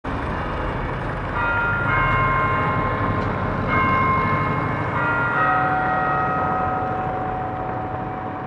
Campanadas a la media hora